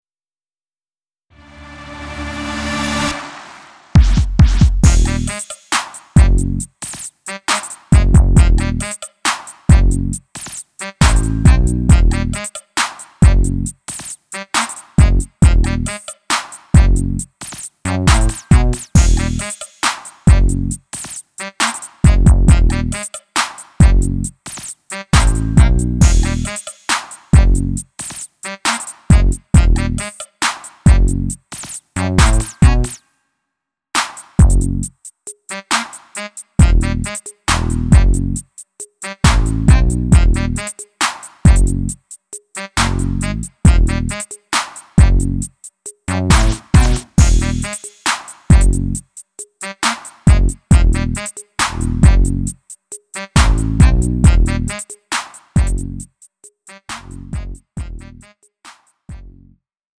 음정 원키 3:12
장르 구분 Lite MR